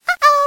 SFX尴尬啊哦 000音效下载
SFX音效